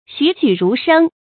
注音：ㄒㄩˇ ㄒㄩˇ ㄖㄨˊ ㄕㄥ
栩栩如生的讀法